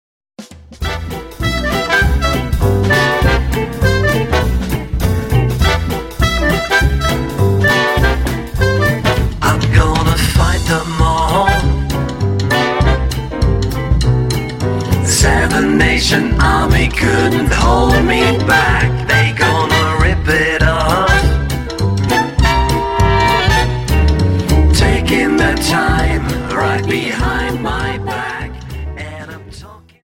Dance: Quickstep Song
Quickstep 50